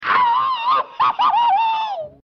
Sound Buttons: Sound Buttons View : Tom Scream
tom_scream_ow.mp3